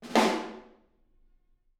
R_B Snare Roll - Room.wav